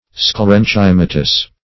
Search Result for " sclerenchymatous" : The Collaborative International Dictionary of English v.0.48: Sclerenchymatous \Scler`en*chym"a*tous\, a. (Bot.
sclerenchymatous.mp3